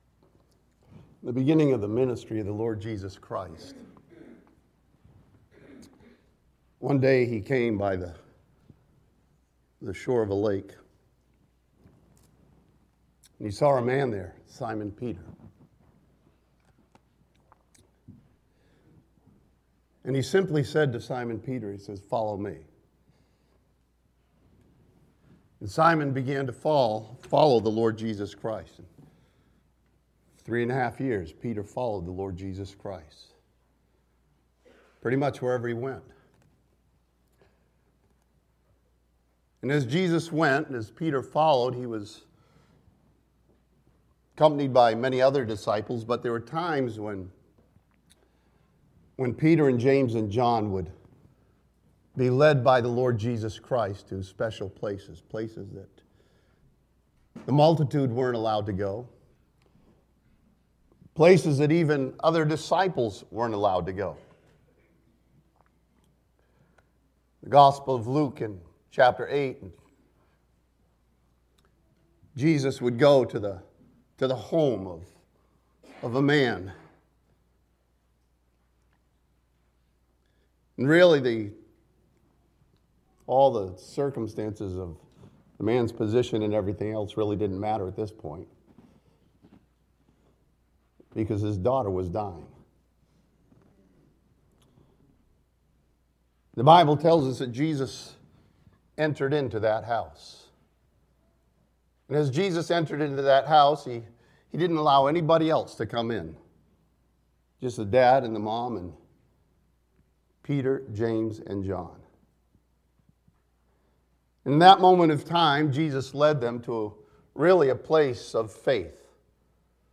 This sermon from Luke chapter 22 follows Jesus as He prays to His Father and sees our place of prayer.